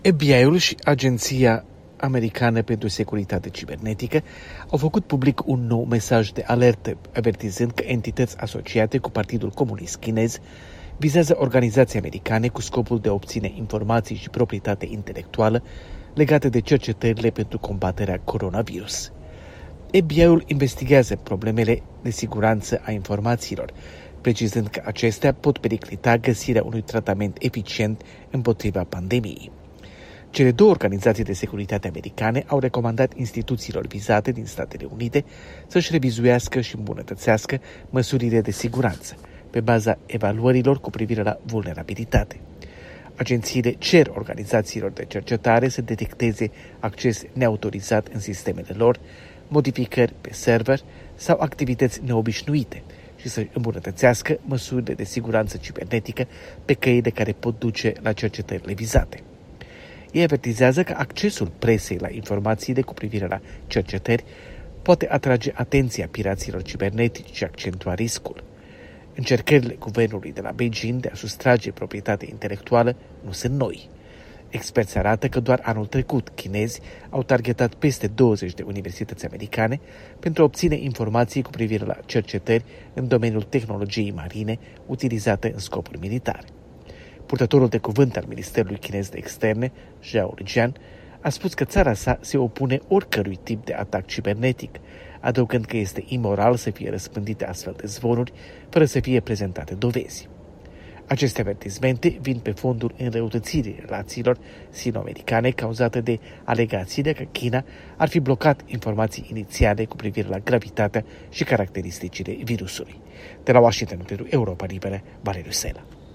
Corespondență de la Washington: FBI